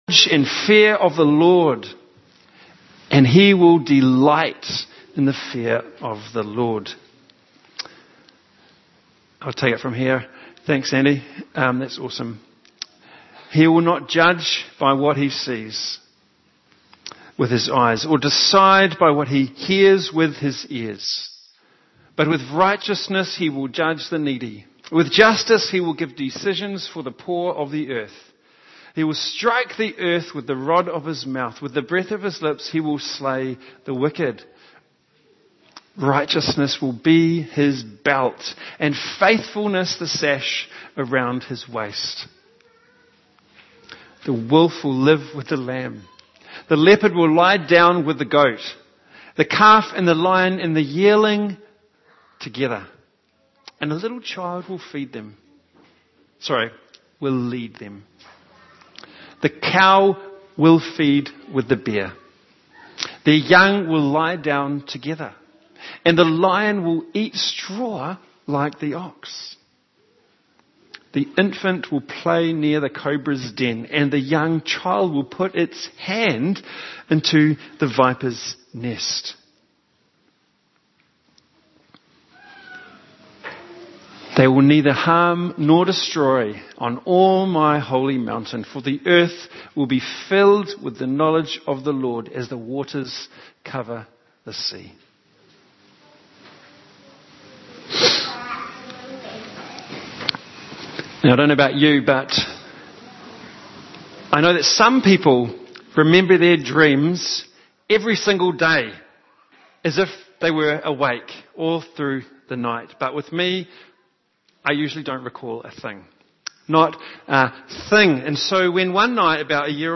Passage: Isaiah 11:1-9 Service Type: Family